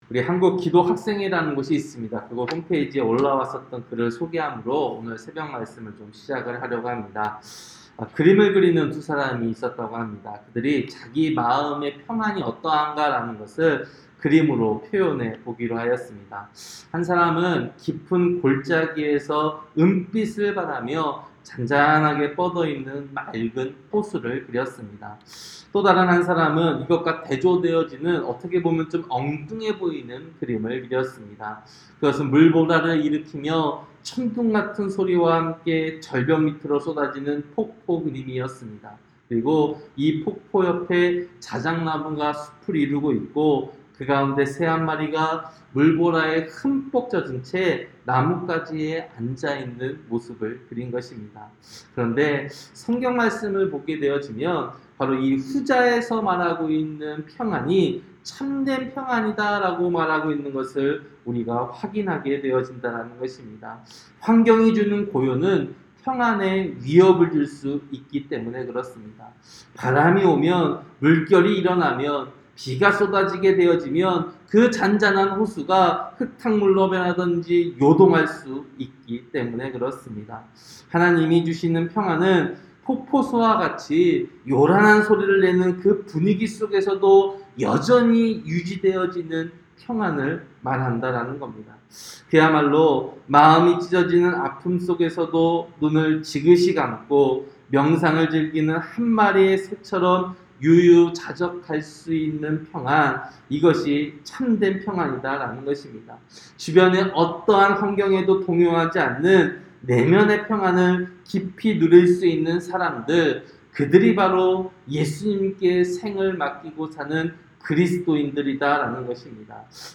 새벽설교-사사기 2장